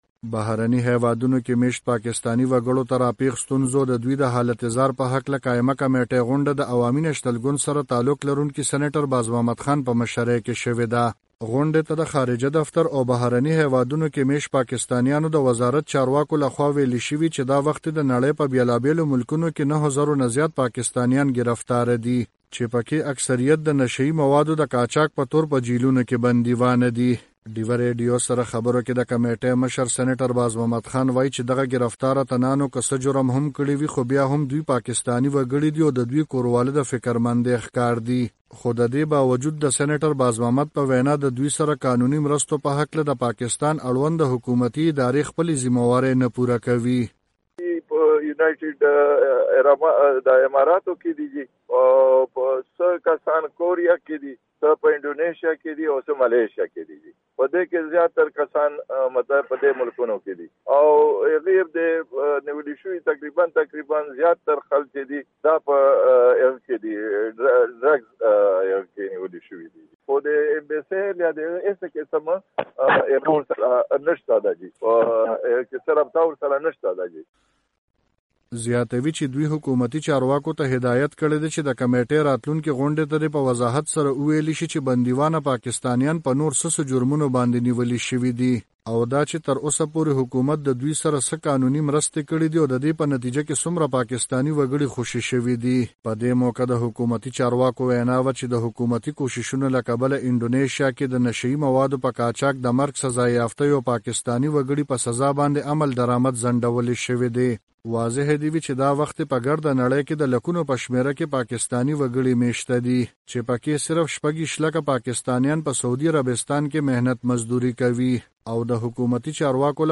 رپورټ